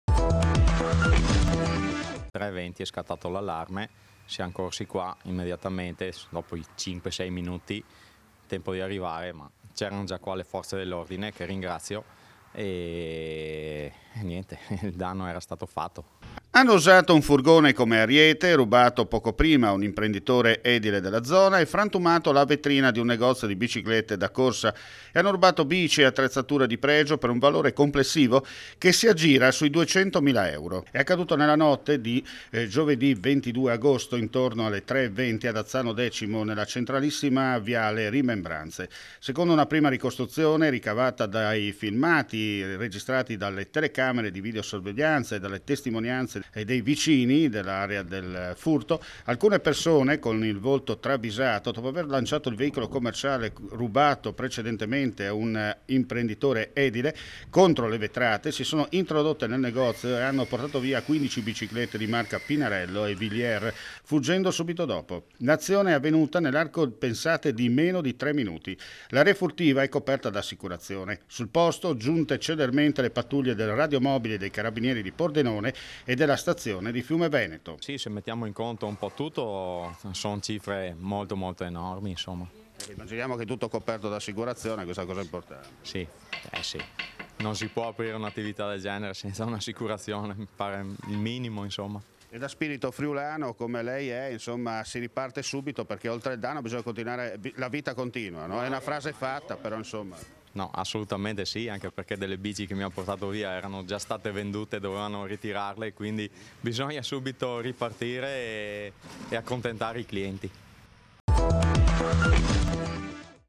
Audio intervista